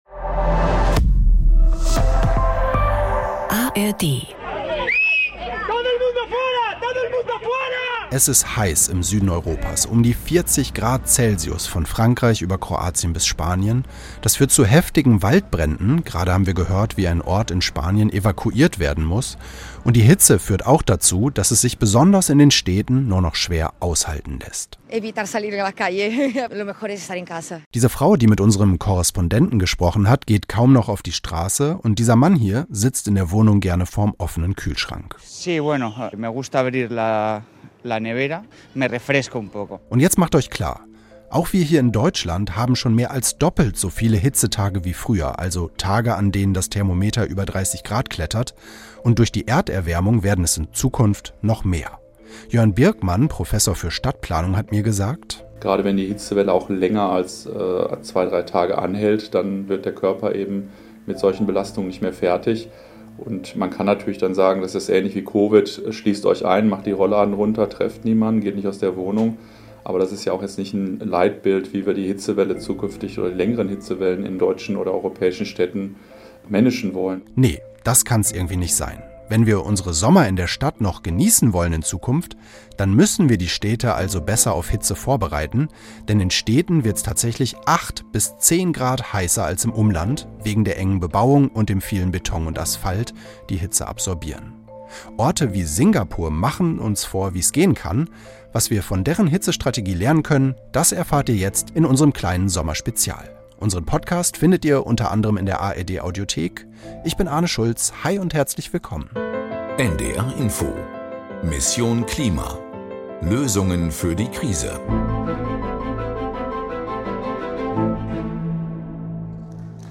Inwiefern wir daraus lernen können und wie wir in Deutschland den Umbau hinkriegen, bespricht Host